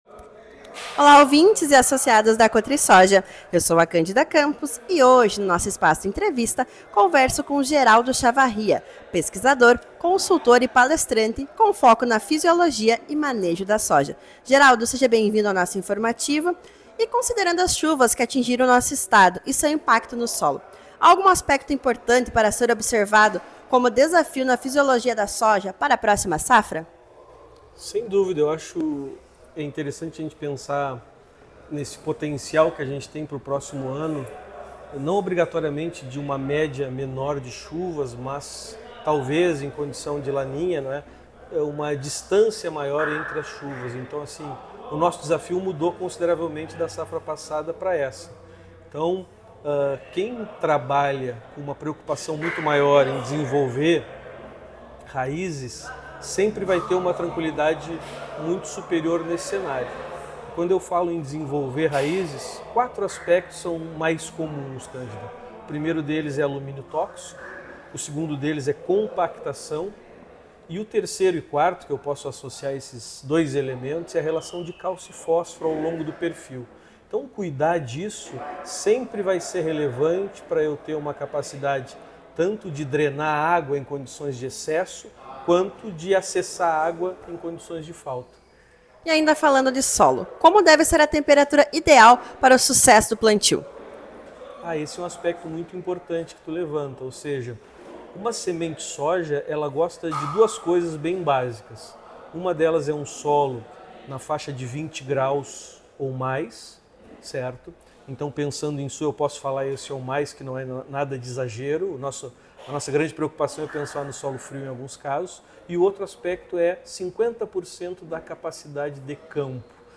Nesta entrevista exclusiva